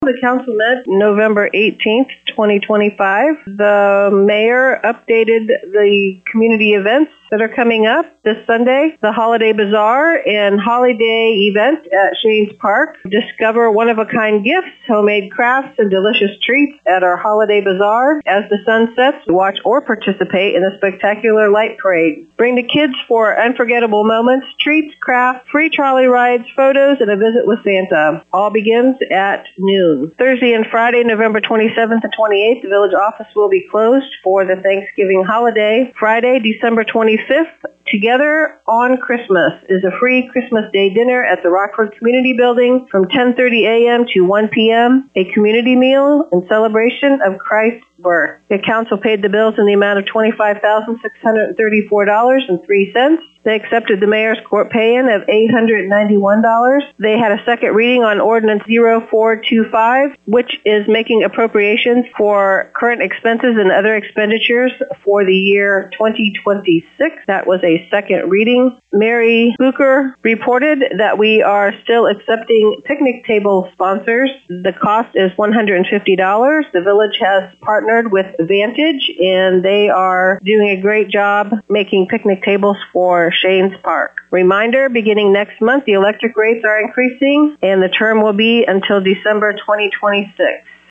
To hear the summary with Rockford Clerk Treasurer Lisa Kuhn: